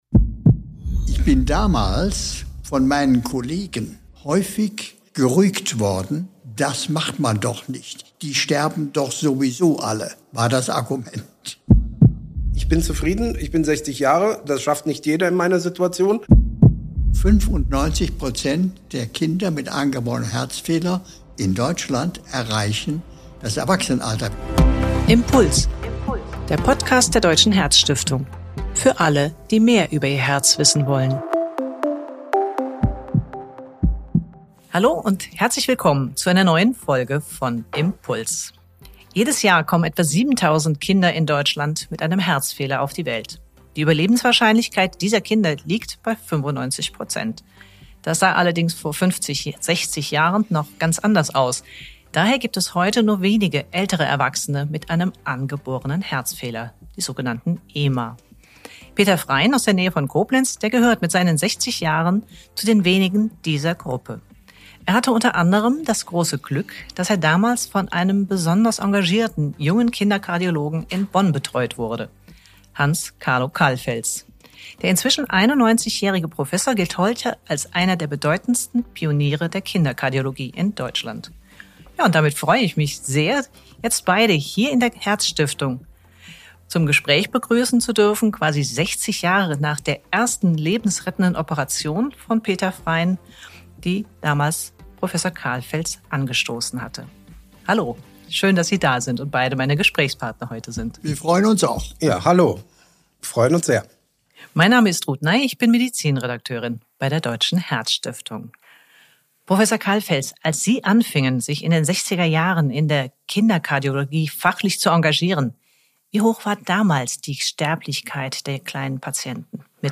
Ein Kinderkardiologe und sein Patient erzählen